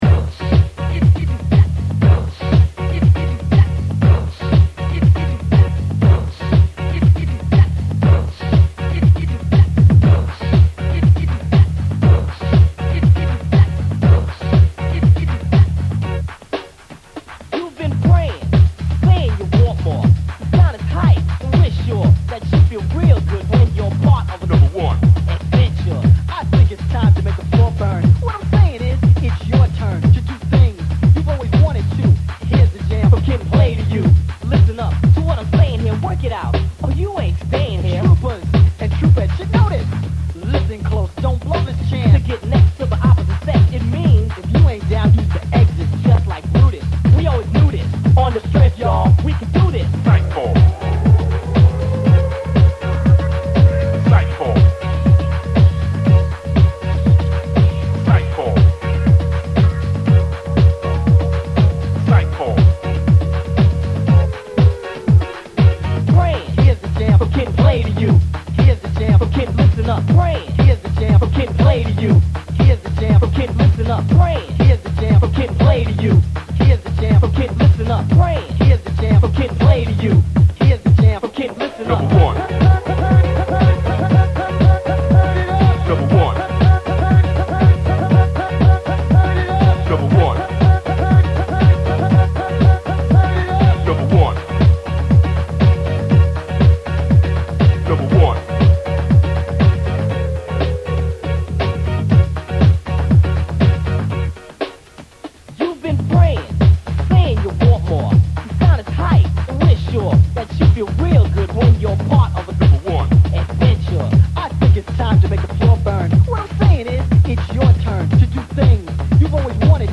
This is a nice mix that's full of great house tunes.